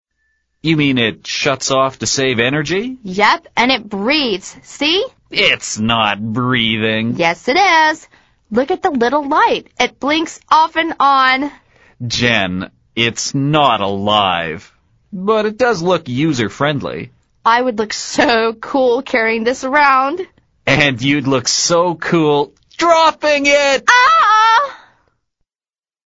美语会话实录第96期(MP3+文本):It does look user-friendly